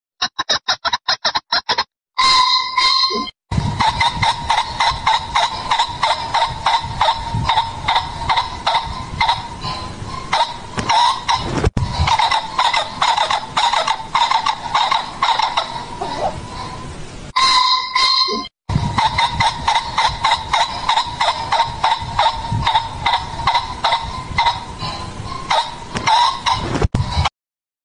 雉鸡打鸣声